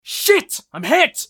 eft_usec_wound7.mp3